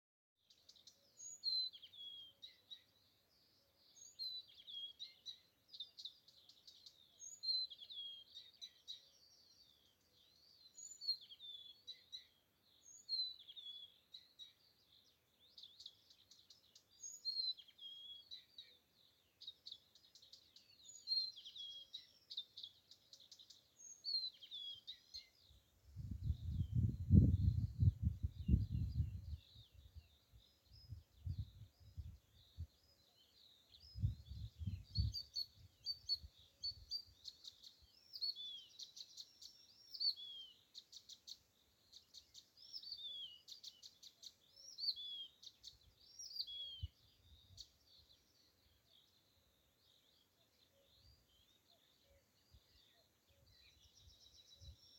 садовая камышевка, Acrocephalus dumetorum
Administratīvā teritorijaKocēnu novads
СтатусПоёт